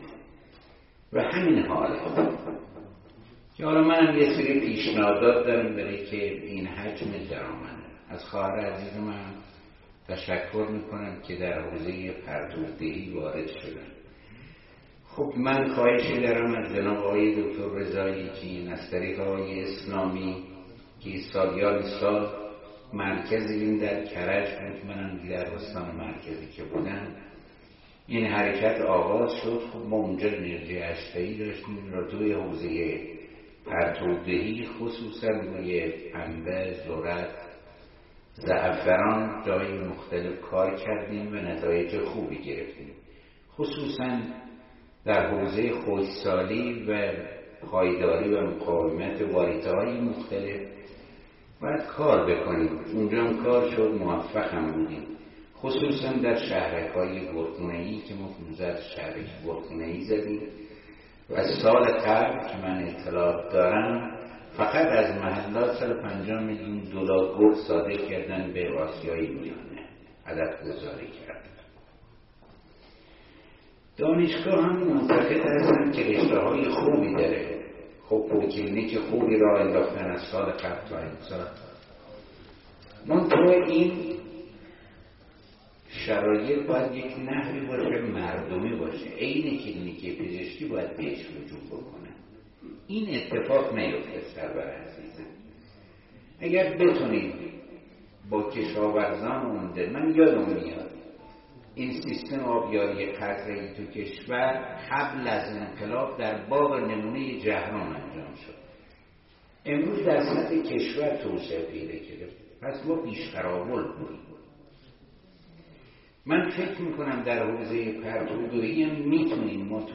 گفتگوی استاندار سابق مرکزی در دانشگاه جهرم